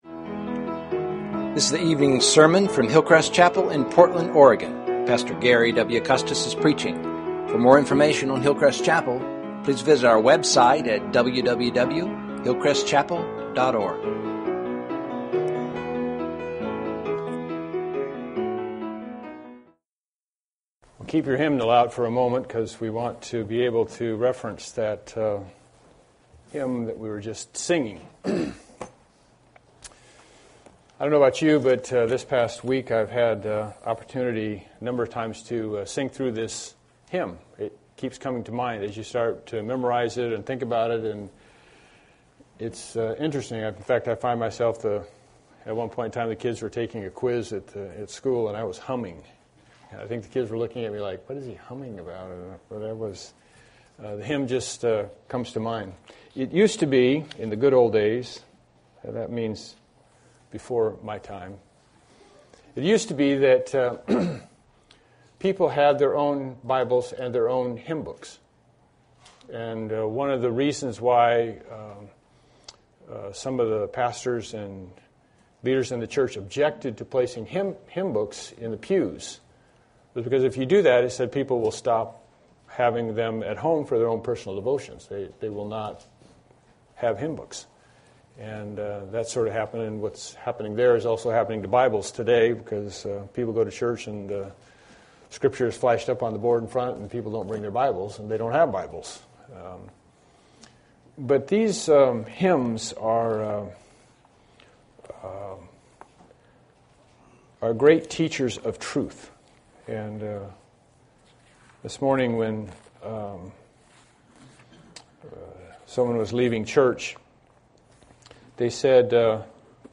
Series: Evening Sermons